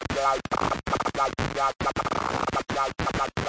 游戏SFX " 眩晕
描述：就像机器人的电子特技一样
标签： 眩晕 实验室 游戏 计算机 空间战 机器人 损坏 空间 街机 激光 视频游戏
声道立体声